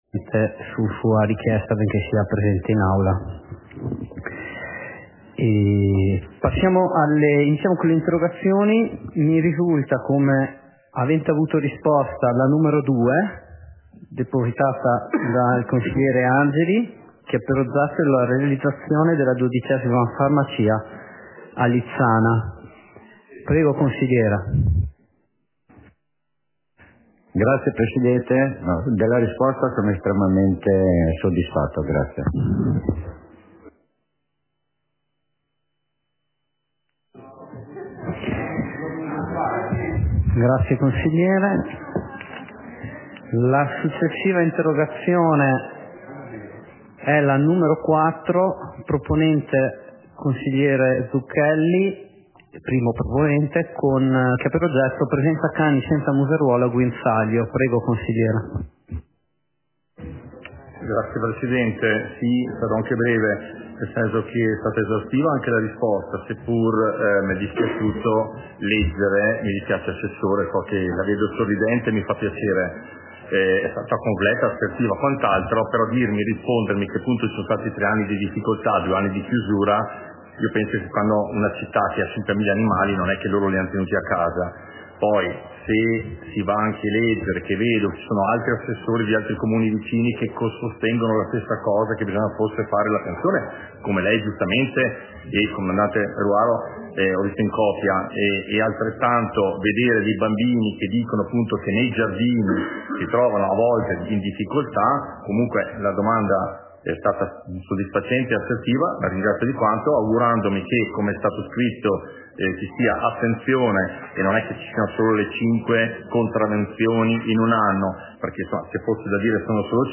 Seduta del consiglio comunale - 01.02.2023